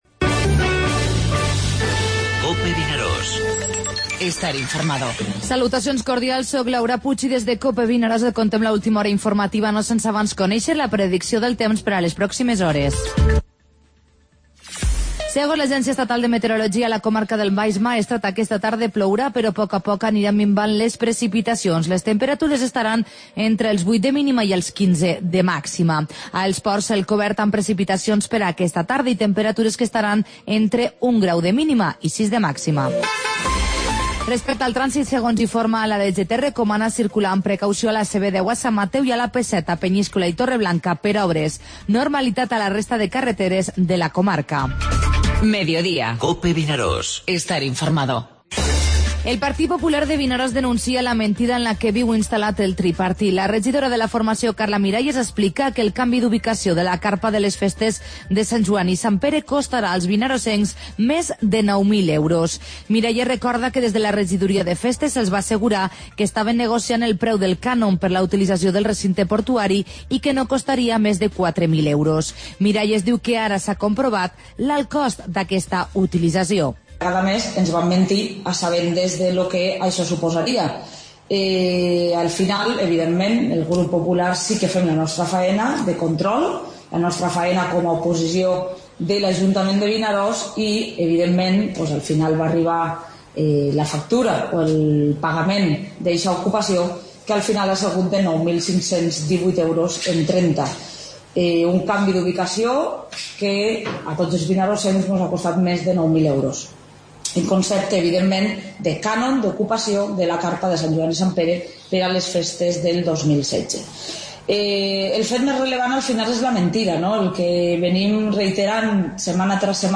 Informativo Mediodía COPE al Maestrat (divendres 27 de gener)